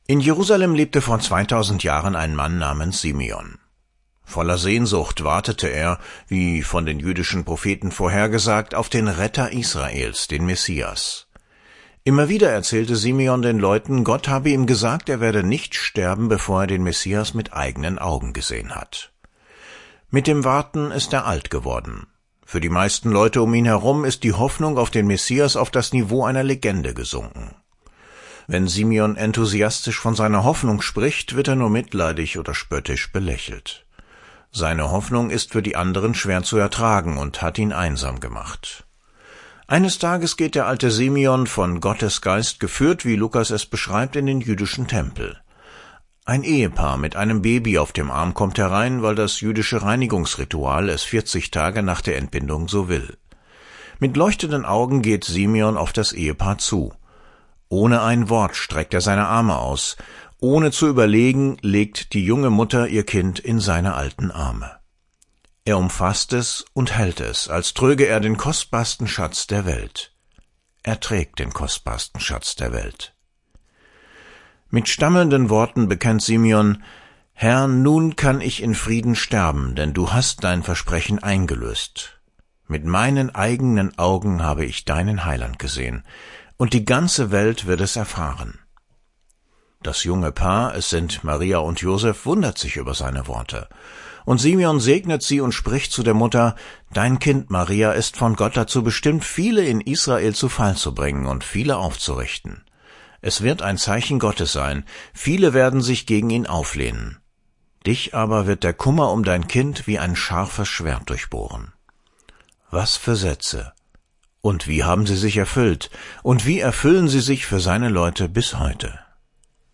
Diesen Radiobeitrag